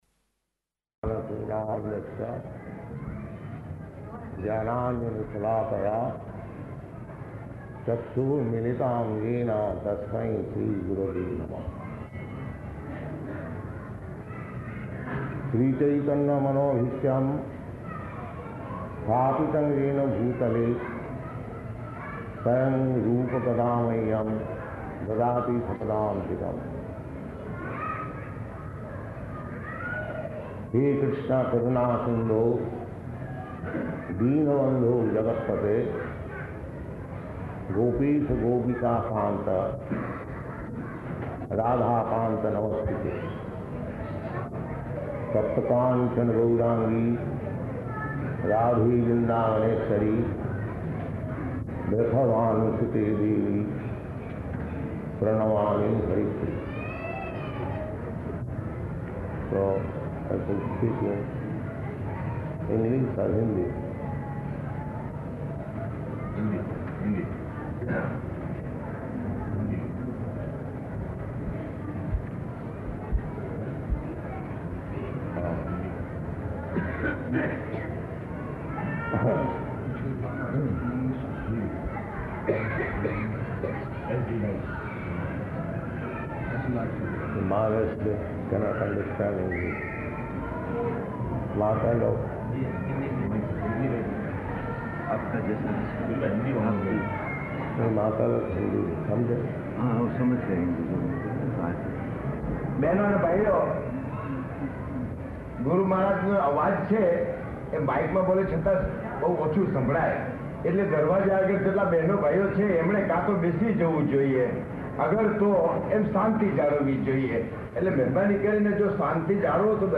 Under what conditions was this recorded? Location: Mombasa